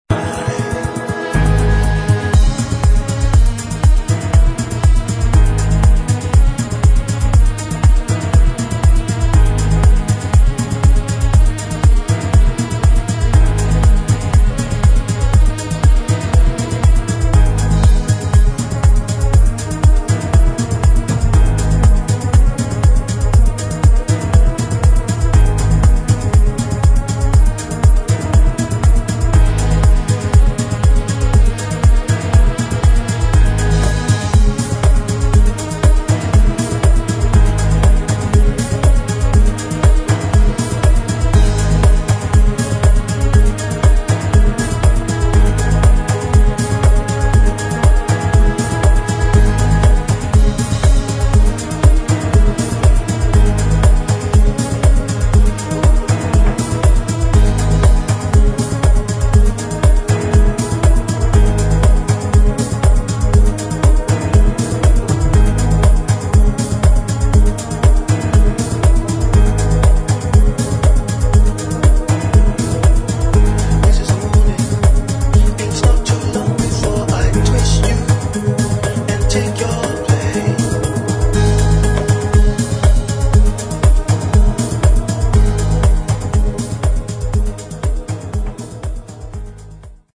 [ HOUSE | DUB | ELECTRONIC ]